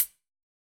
UHH_ElectroHatC_Hit-19.wav